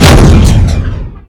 follyFire.ogg